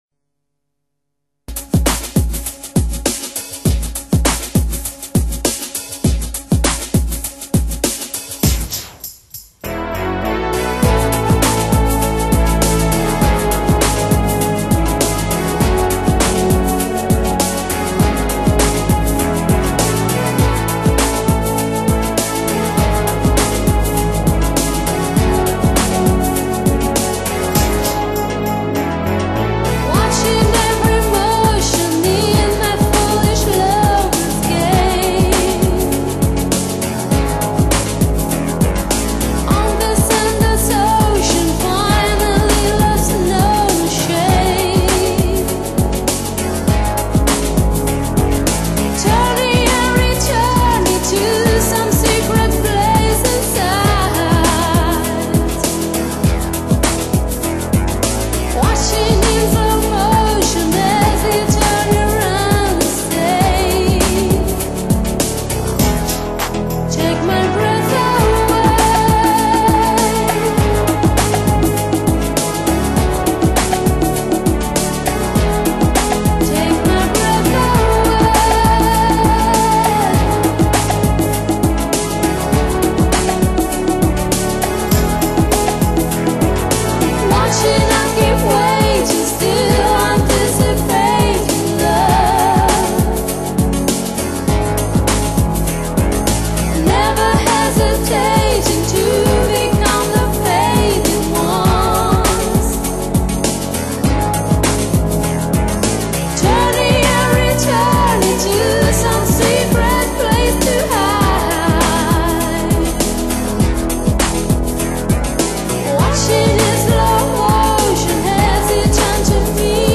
介绍： Italo disco